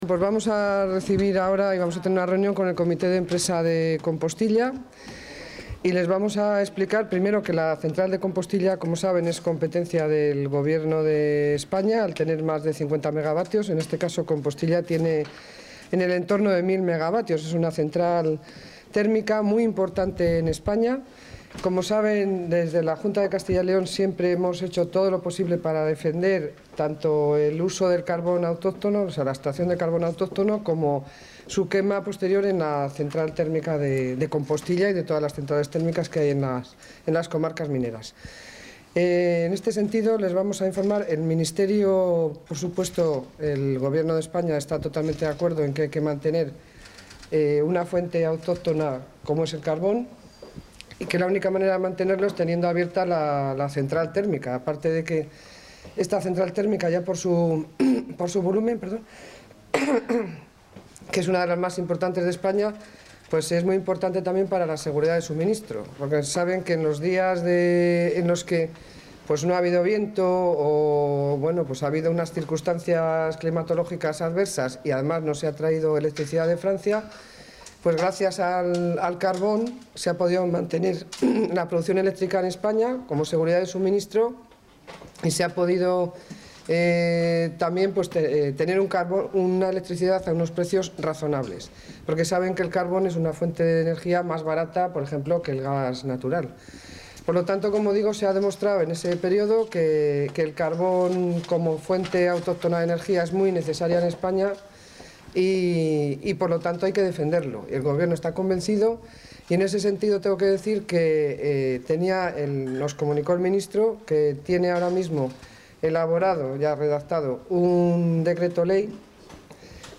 Declaraciones de la consejera de Economía y Hacienda.